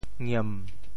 验（驗） 部首拼音 部首 马 总笔划 10 部外笔划 7 普通话 yàn 潮州发音 潮州 ngiem7 文 中文解释 验 <名> (形声。